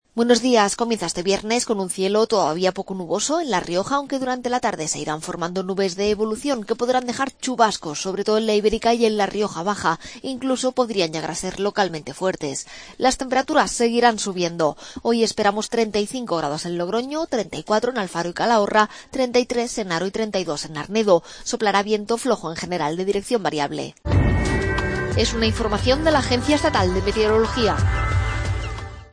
AUDIO: Previsión meteorológica.